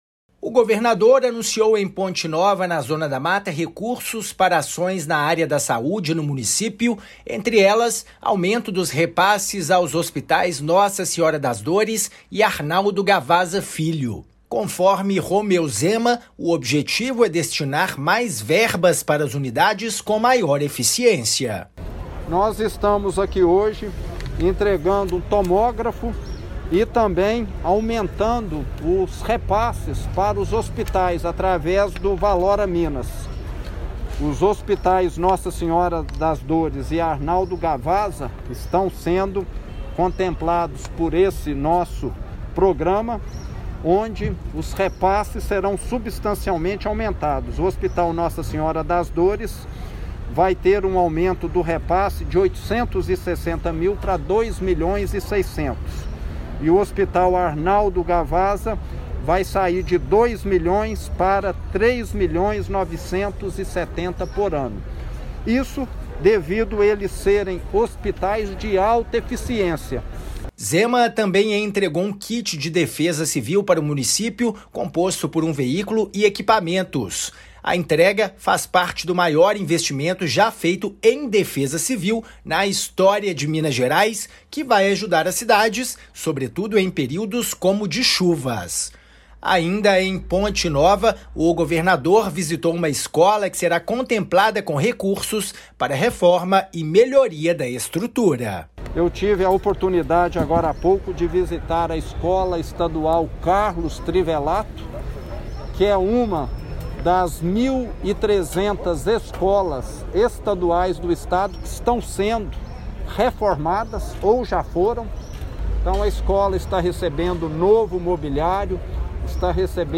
Hospitais Nossa Senhora das Dores e Arnaldo Gavazza terão aumento nos repasses anuais dentro da nova política de Estado Valora Minas. Ouça a matéria de rádio.
MATÉRIA_RÁDIO_GOVERNADOR_PONTE_NOVA.mp3